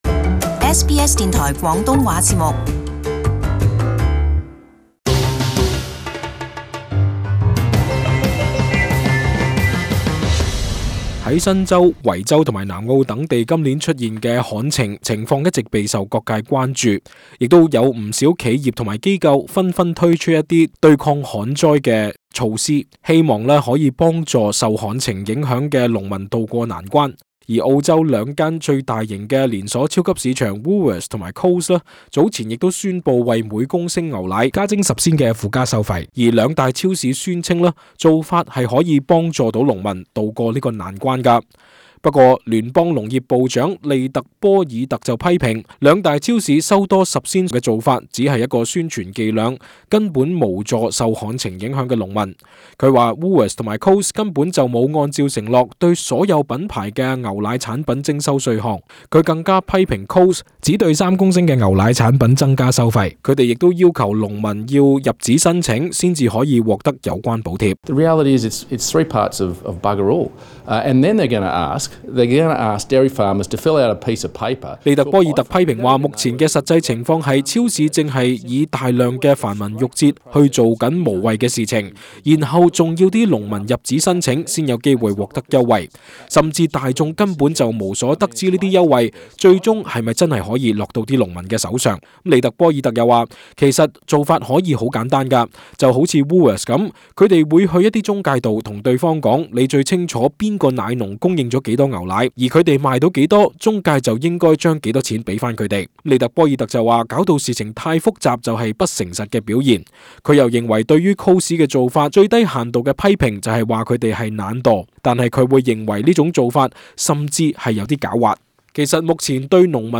【時事報導】農業部長批評十仙牛奶附加費無助農民抗旱